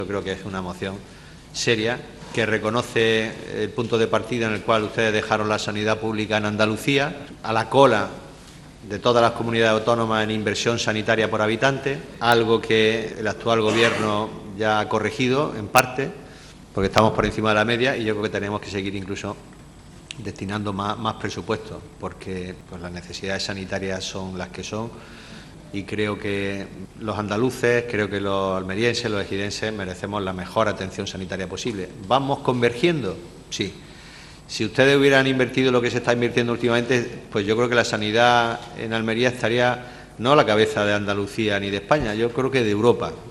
Durante el Pleno celebrado hoy, Góngora ha recordado al PSOE que “no se está desmantelando el Sistema Sanitario Público Andaluz.